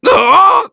Oddy-Vox_Damage_02_kr.wav